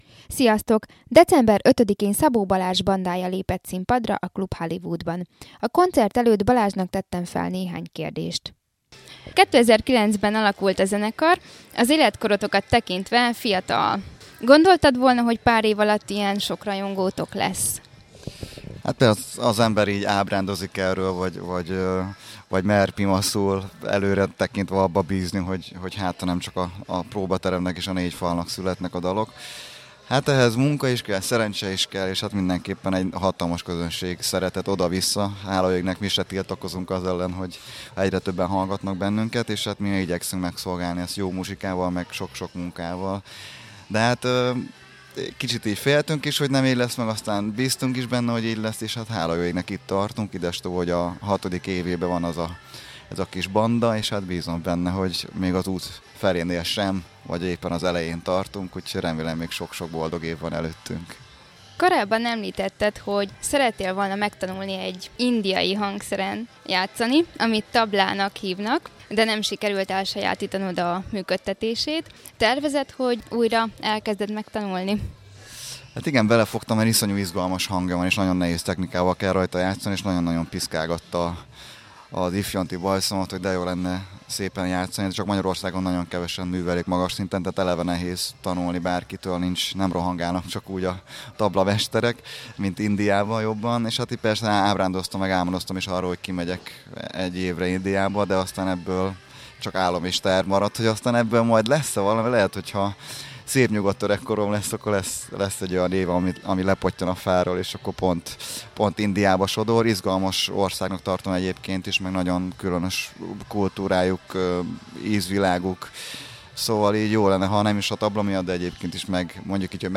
A koncert előtt Balázsnak tettem fel néhány kérdést, amit a lejátszás gombra kattintva hallgathattok meg.
szabo_balazs_interju.mp3